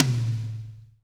-DRY TOM 3-L.wav